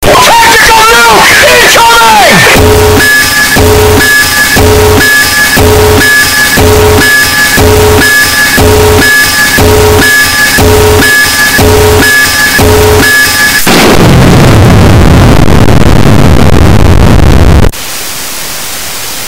мощные
взрывные
очень громкие